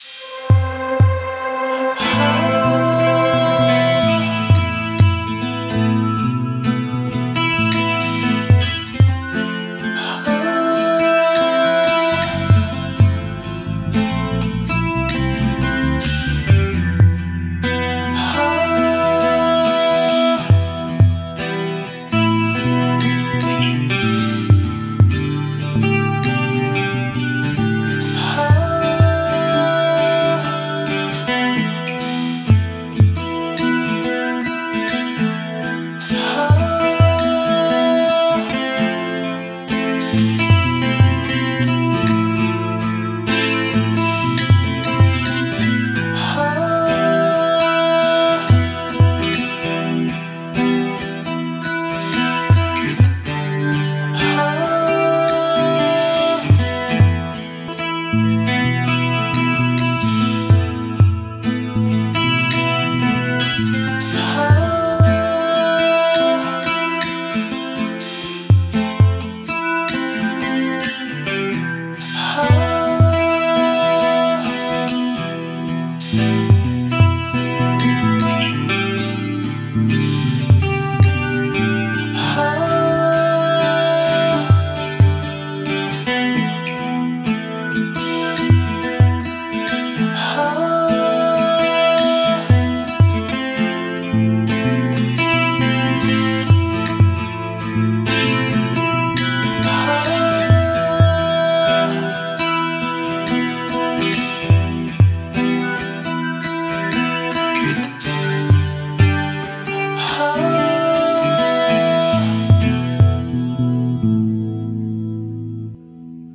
musique zen
composition instrumentale minimaliste et émouvante